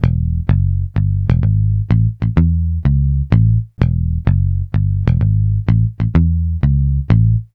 Swingerz 4 Bass-E.wav